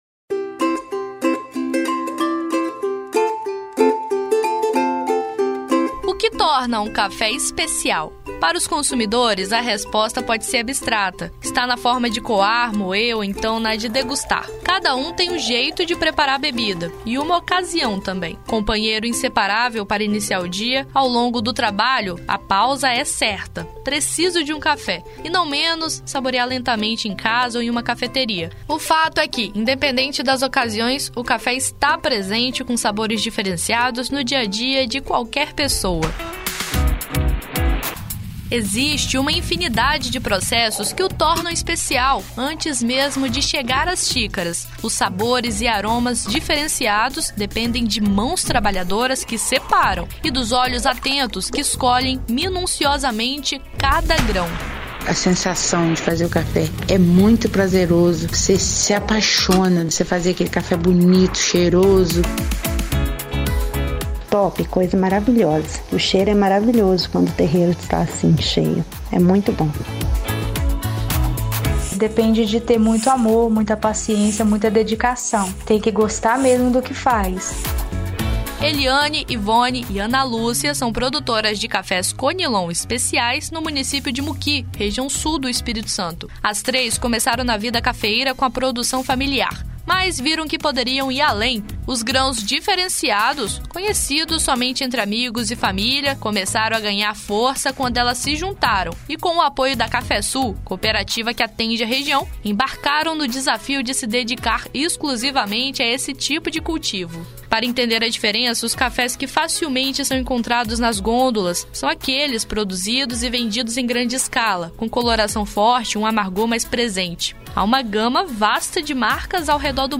Essas são algumas das frases ditas pelas cafeicultoras entrevistadas para essa reportagem.